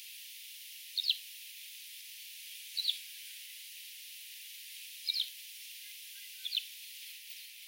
Laverca
Alauda arvensis
Canto
Durante este voo de exhibición, a Laverca común canta de maneira continua, producindo trinos e notas rápidas que poden durar varios minutos.